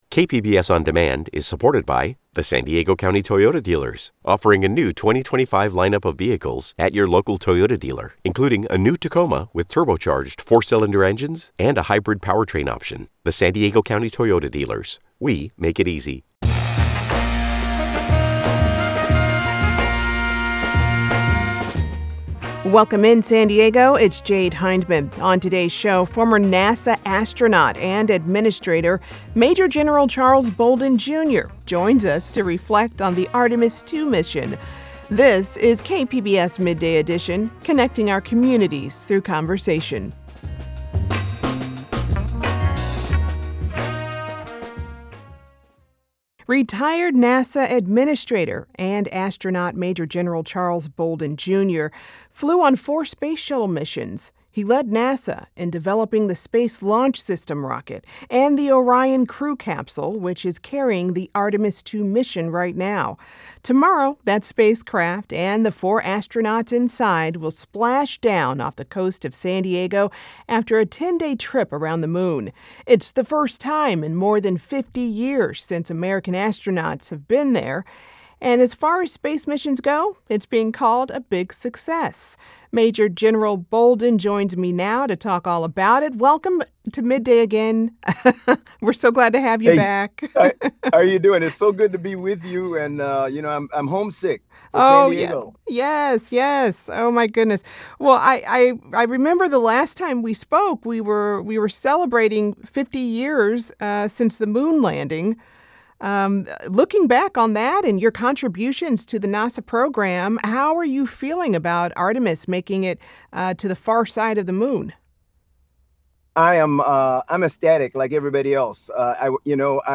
On Midday Edition, we sit down with former astronaut and NASA administrator Major General Charles Bolden Jr. to hear about his career and his insights about the significance of the Artemis II mission. Guest: Major General Charles Bolden Jr. , former astronaut and NASA administrator